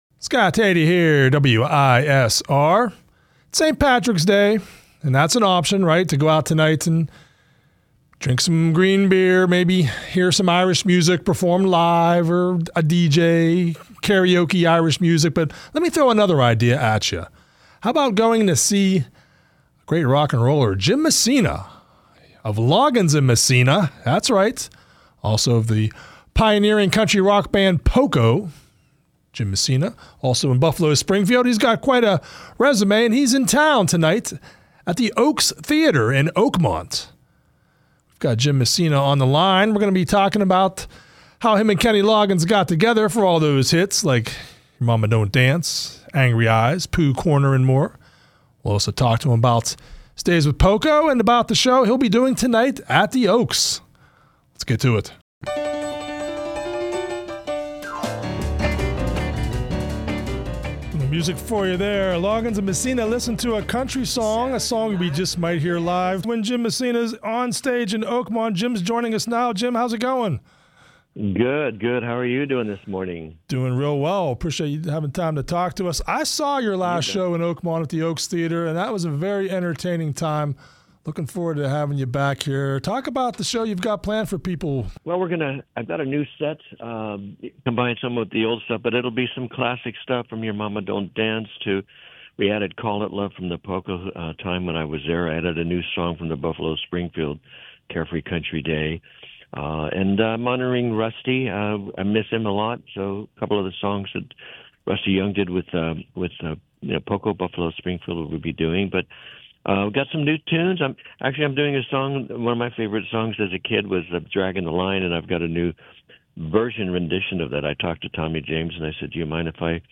Messina phoned into the Beaver County Radio Morning Show on Monday to talk about his Oakmont concert.